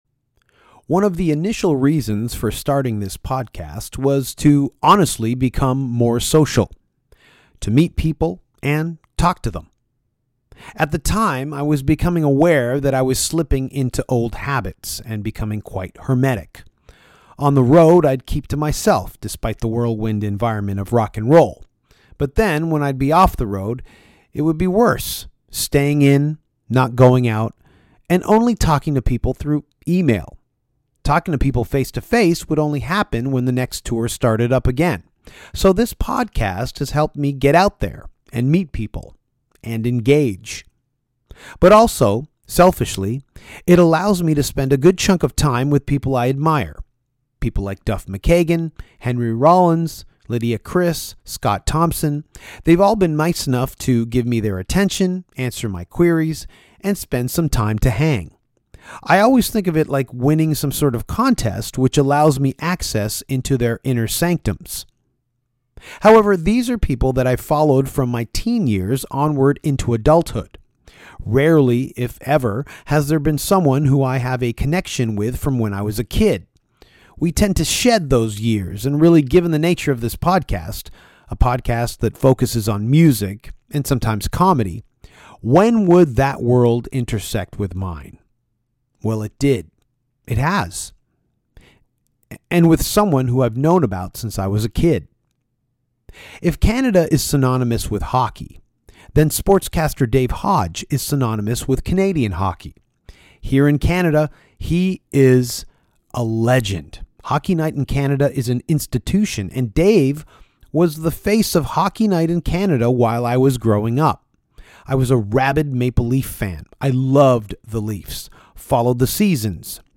The one and only Dave Hodge, legendary NHL hockey sportscaster, sat down with Danko to talk music, and only music! Dave is the biggest music fan Danko knows and this episode is the proof.